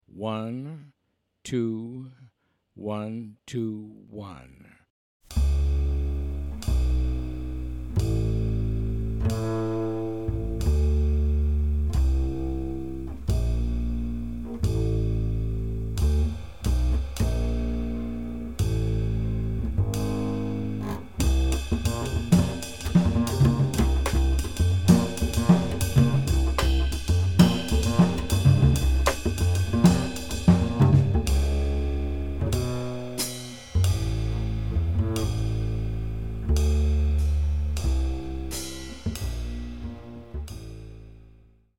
Cool, laid-back, and harmonically rich
1-feel and Latin groove for the melody 2 times